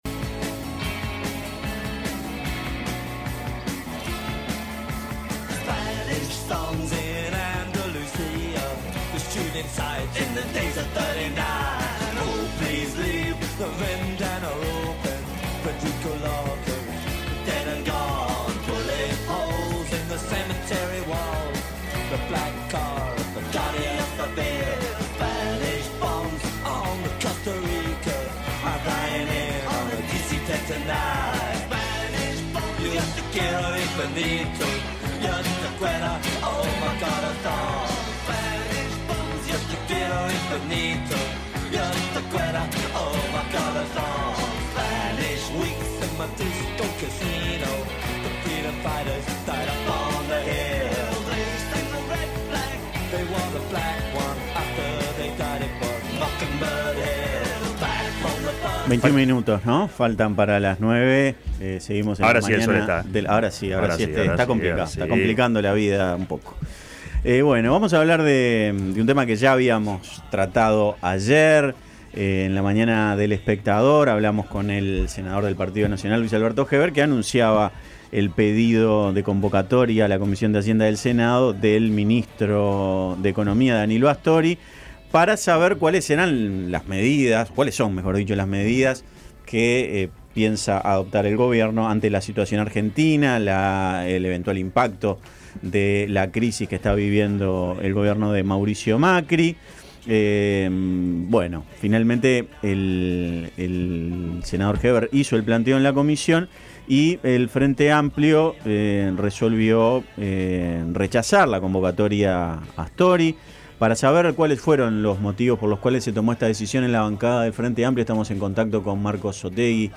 Descargar Audio no soportado Escuche la entrevista completa: Descargar Audio no soportado